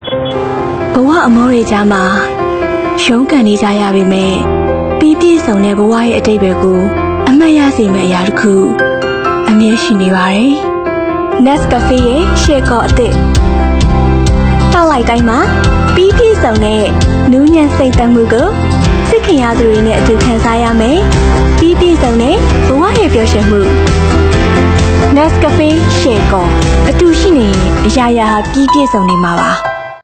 广告【优雅磁性】